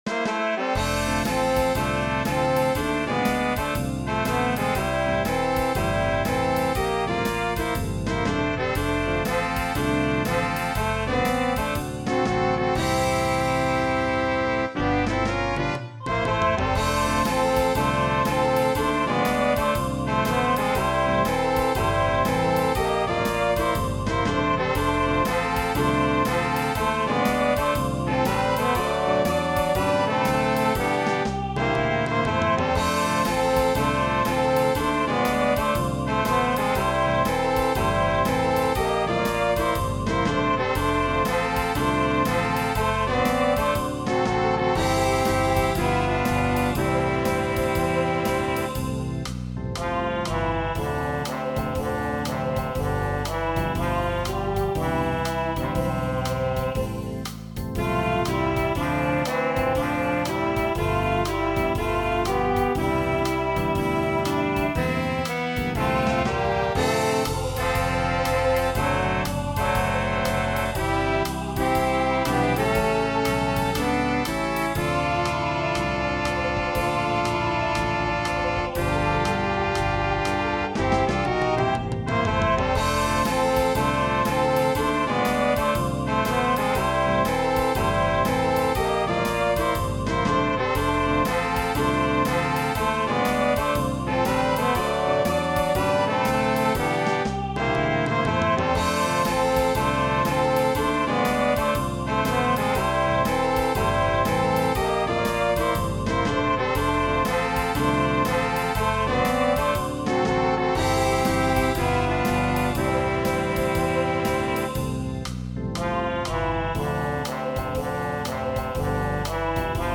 Herbstlager 2015 JW/BR Leuggern in Lantsch (GR): Lagersong